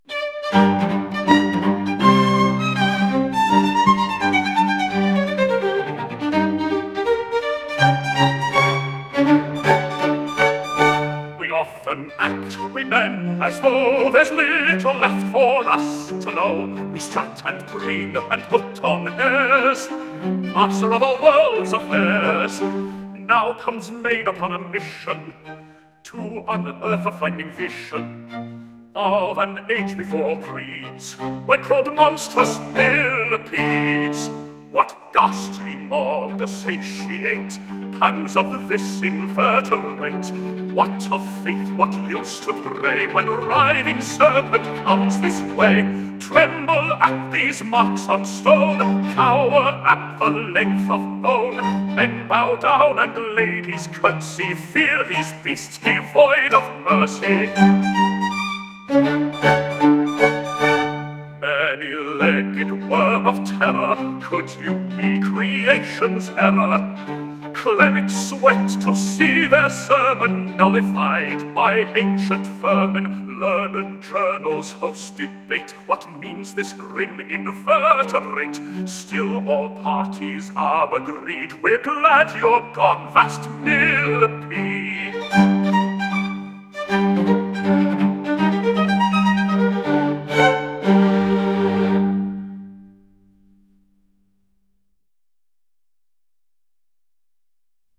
Drawing Room Version